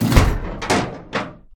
gun-turret-deactivate-03.ogg